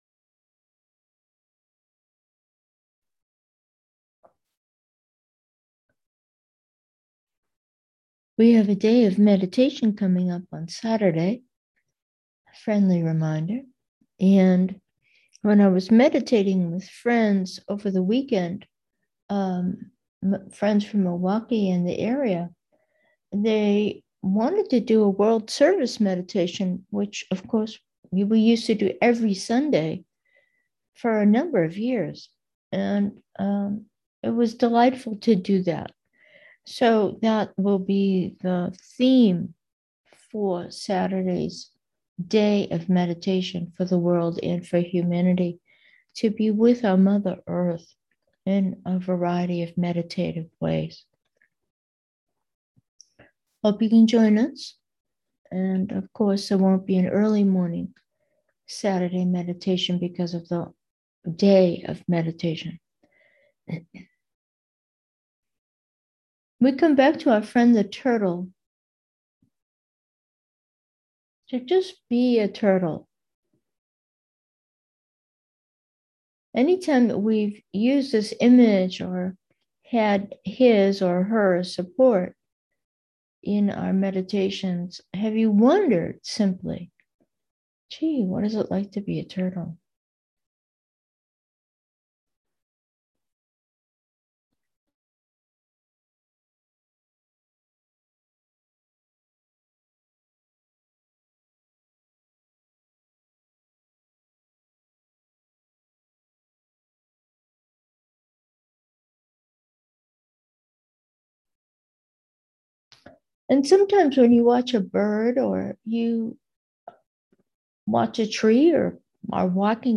Meditation: the senses and awareness 1